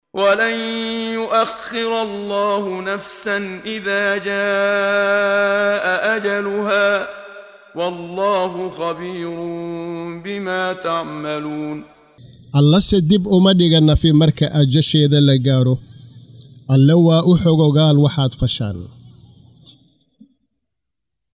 Waa Akhrin Codeed Af Soomaali ah ee Macaanida Suuradda Al-Munaafiquun ( Munaafiqiinta ) oo u kala Qa